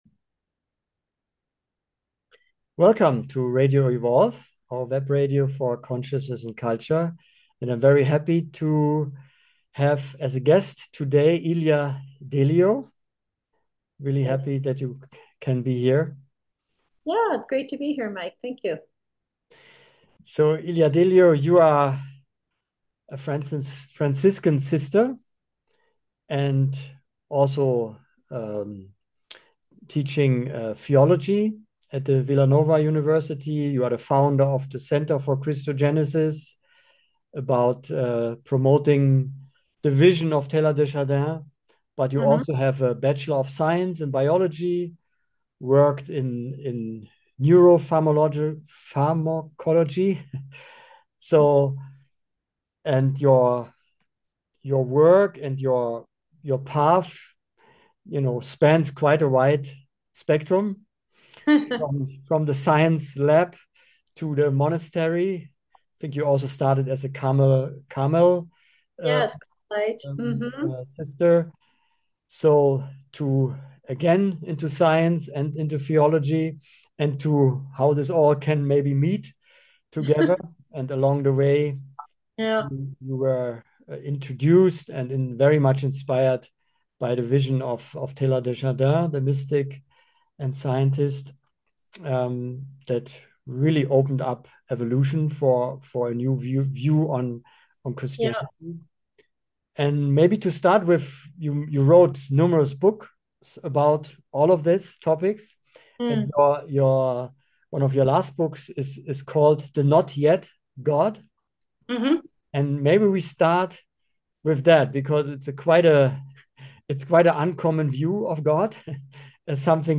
Dialogue